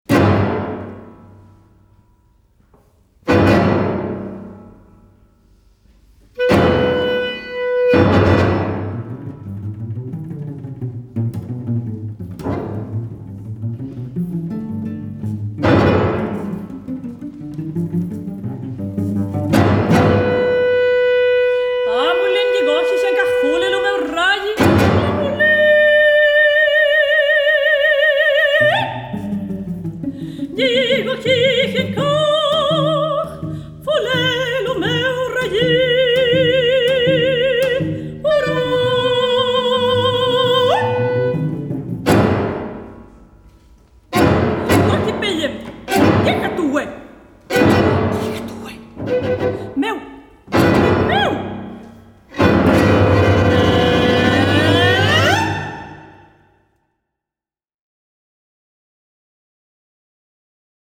Epigramas Mapuches para contralto, clarinete, violín, cello y piano
Música vocal